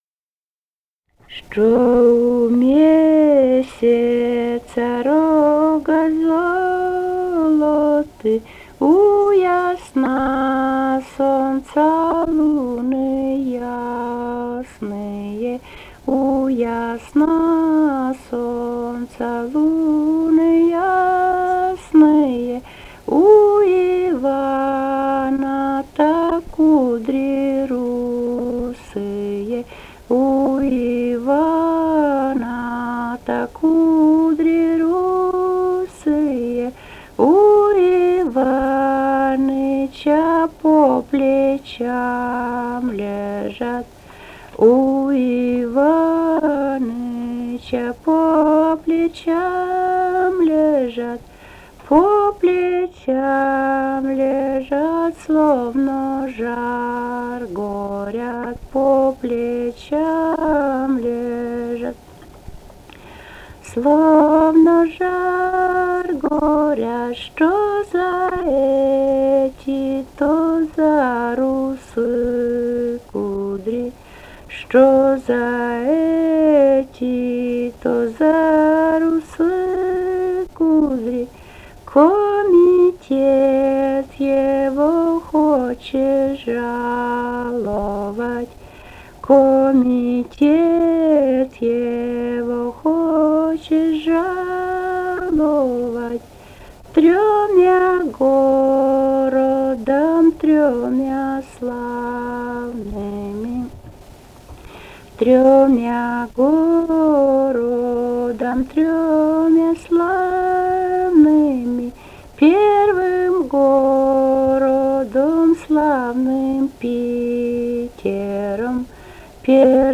Живые голоса прошлого 161. «Что у месяца рога золоты» (свадебная).